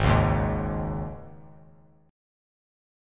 G#sus4 chord